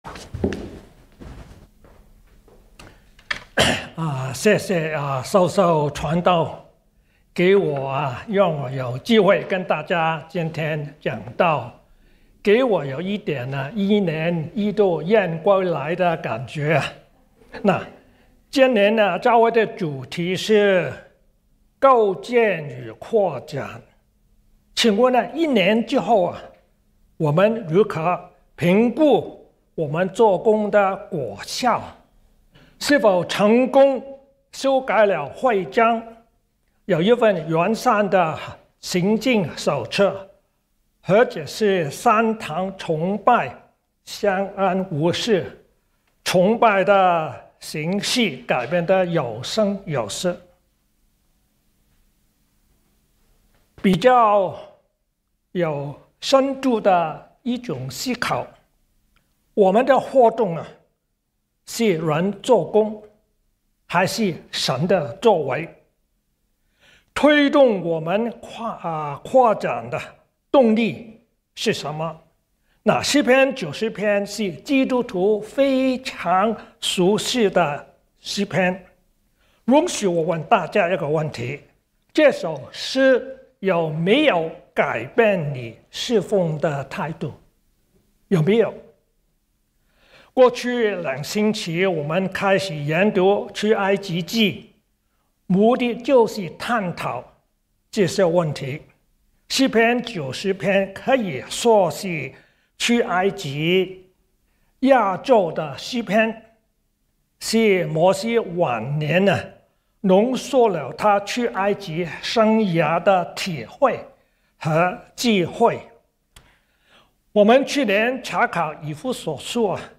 Sermons | 基督教主恩堂